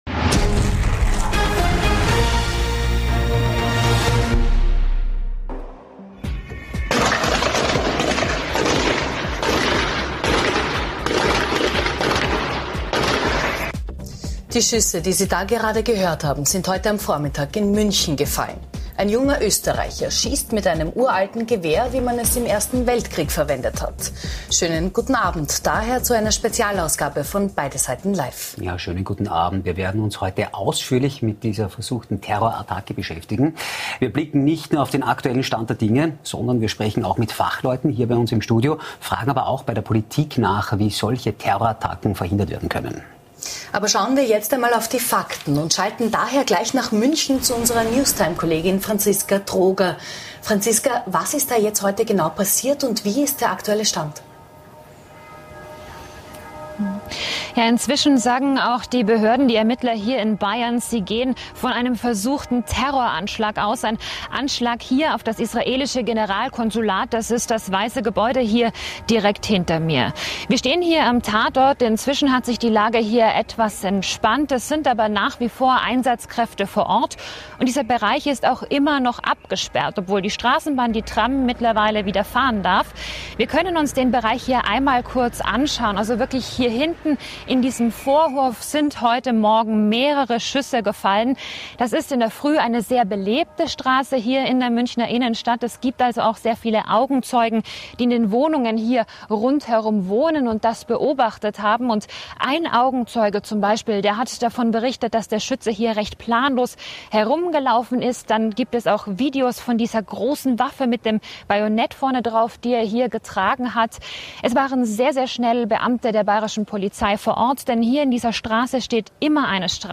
Außerdem im Interview: Christian Hafenecker, Generalsekretär der FPÖ.